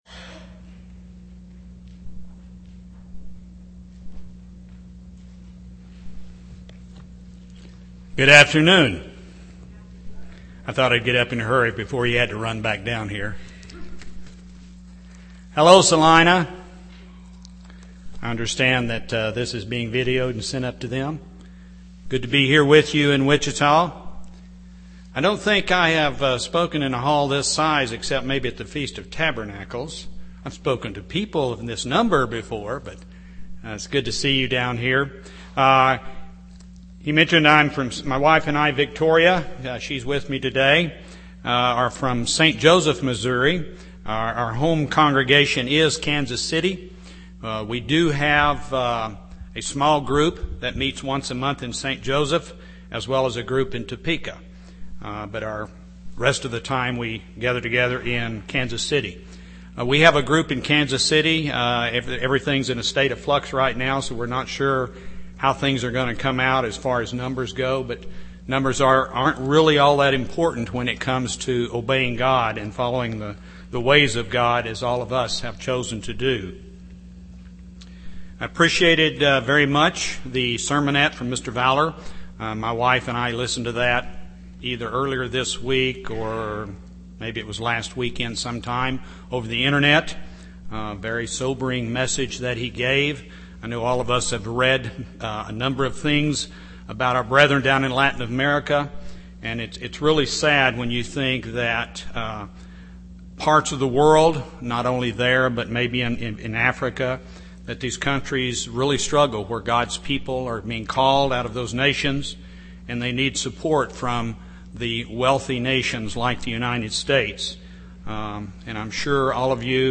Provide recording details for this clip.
Given in Wichita, KS